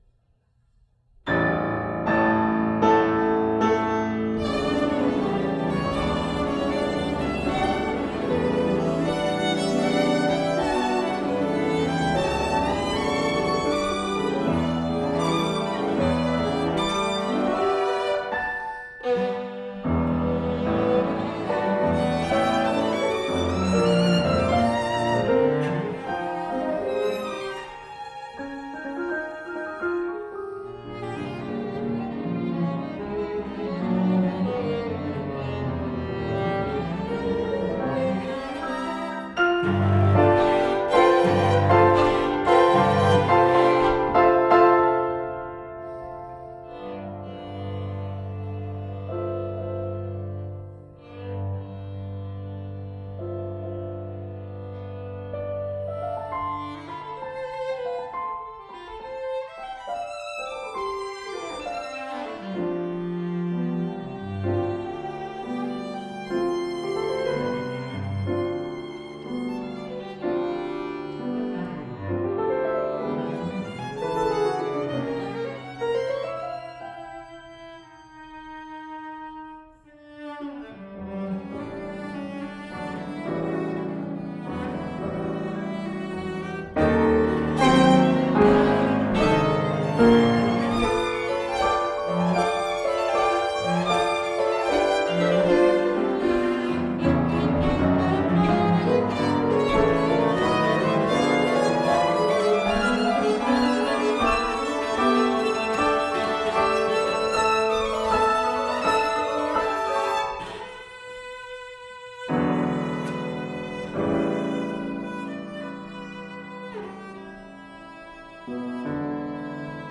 for Violin, Violoncello, and Piano
violin
cello
piano